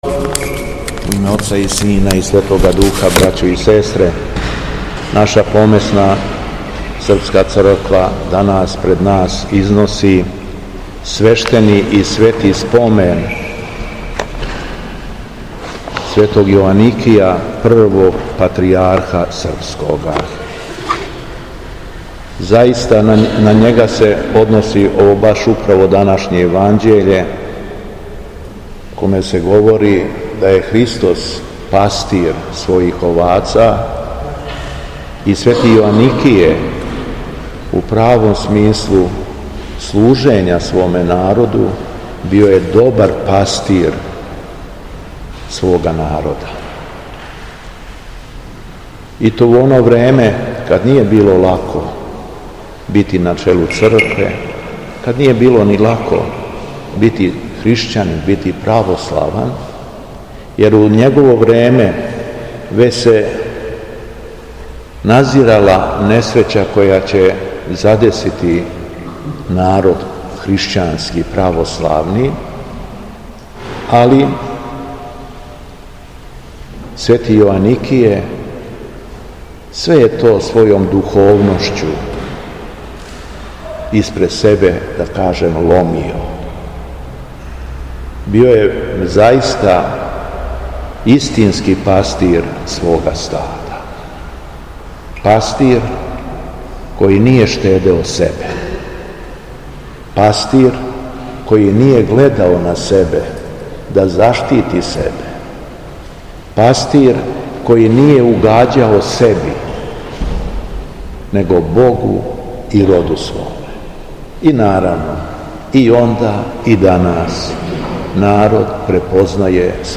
ЛИТУРГИЈА У САБОРНОМ ХРАМУ У КРАГУЈЕВЦУ - Епархија Шумадијска
Беседа Његовог Преосвештенства Епископа шумадијског г. Јована
После прочитаног јеванђелског зачала, Преосвећени Владика је у својој беседи рекао: